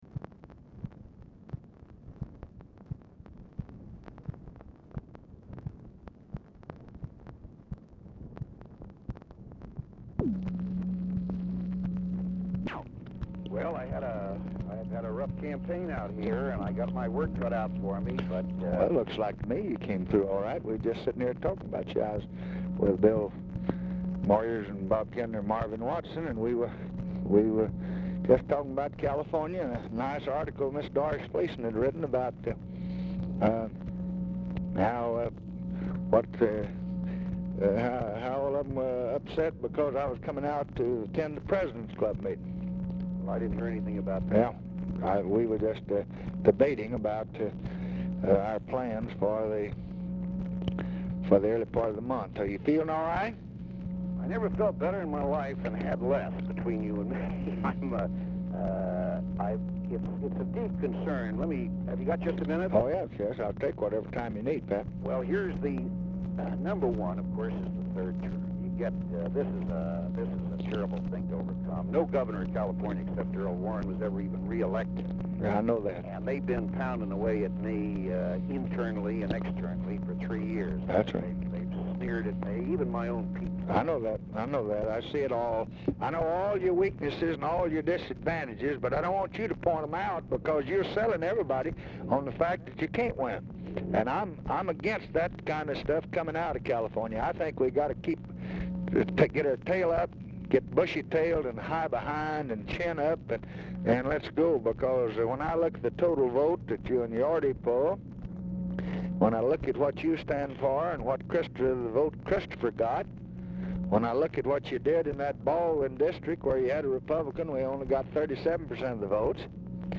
POOR SOUND QUALITY
Format Dictation belt
Specific Item Type Telephone conversation